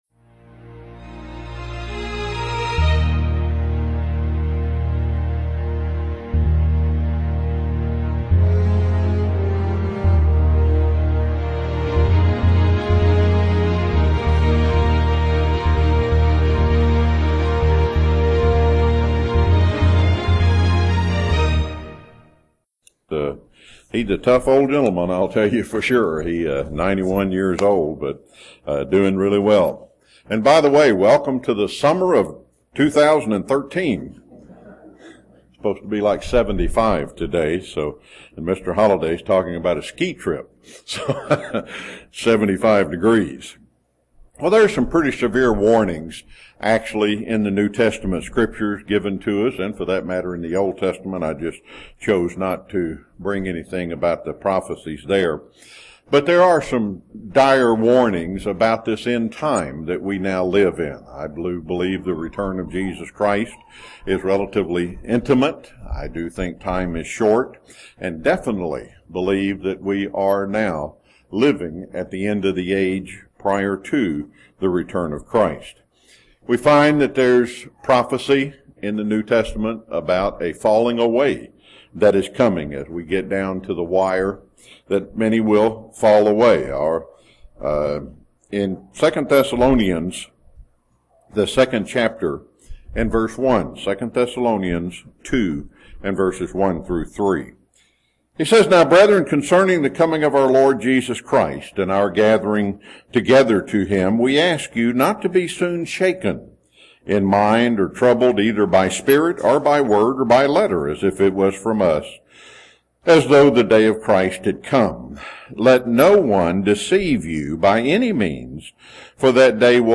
UCG Sermon Studying the bible?
Given in Chattanooga, TN